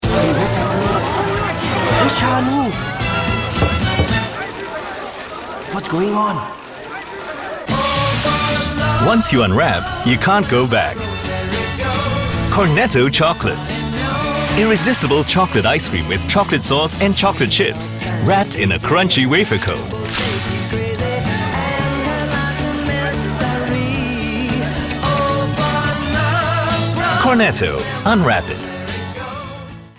English (Singapore)
Promos
Baritone
WarmAuthoritativeConversationalFriendlyDarkEngagingAssuredReliable